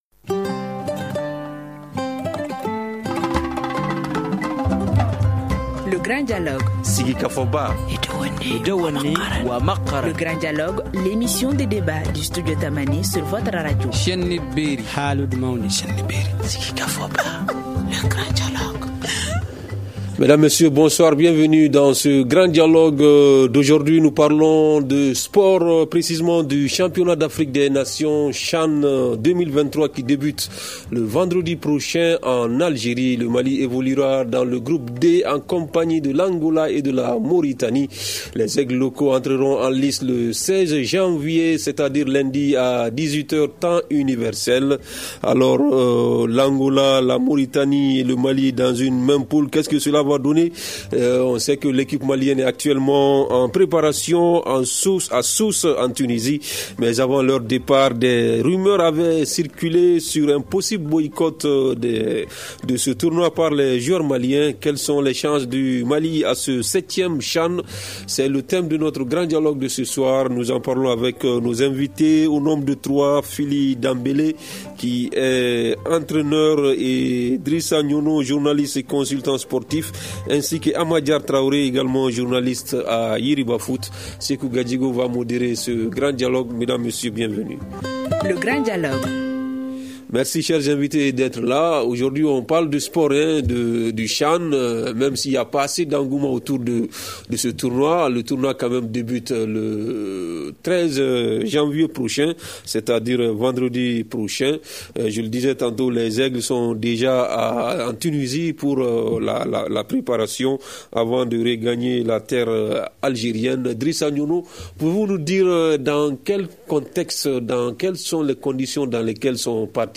Nous en parlons aujourd’hui avec nos invités